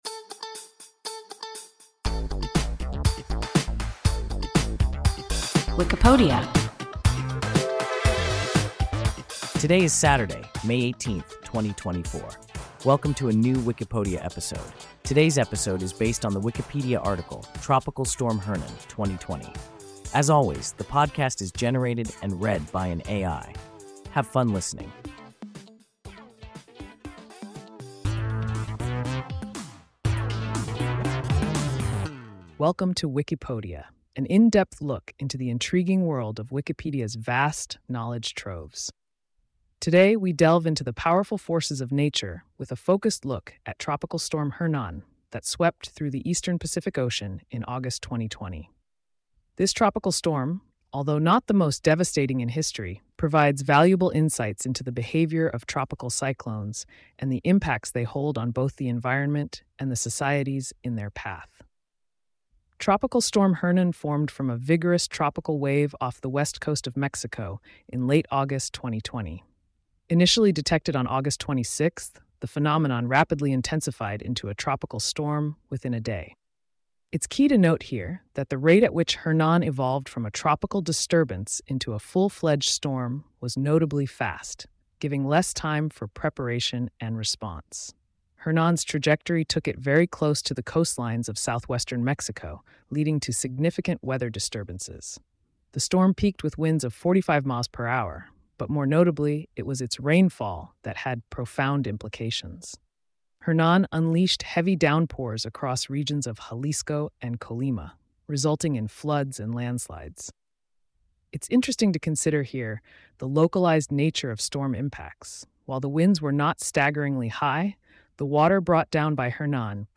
Tropical Storm Hernan (2020) – WIKIPODIA – ein KI Podcast